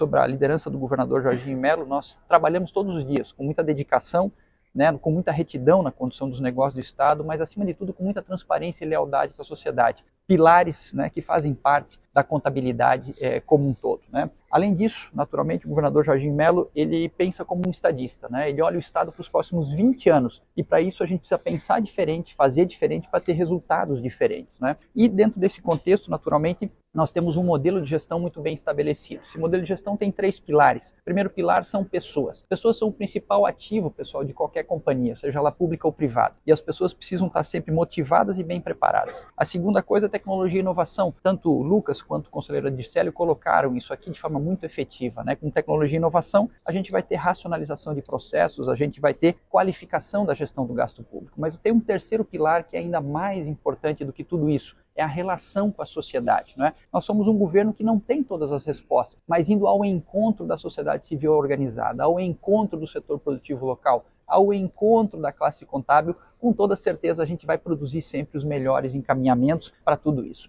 Essa foi uma das mensagens do secretário de Estado da Fazenda, Cleverson Siewert, em sua participação no 21º Congresso Brasileiro de Contabilidade, megaevento realizado no Expocentro Balneário Camboriú.